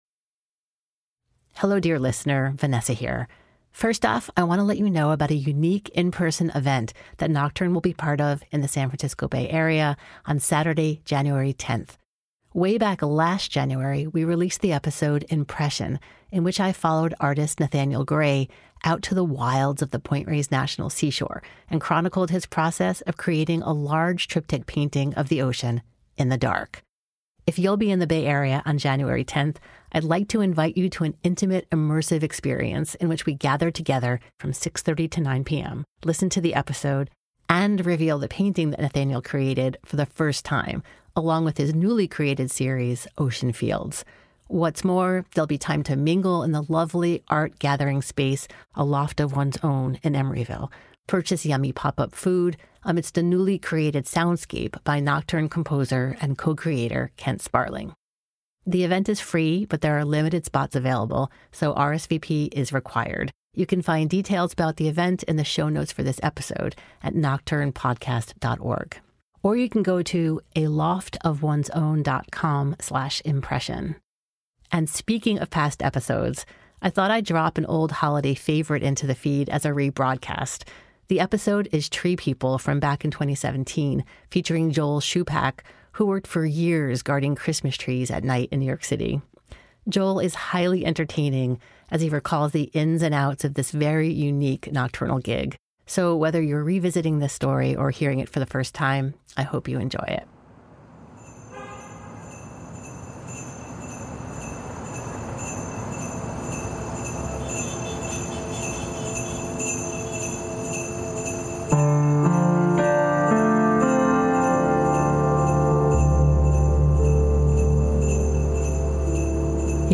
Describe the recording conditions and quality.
Live Event Info + Rebroadcast of Tree People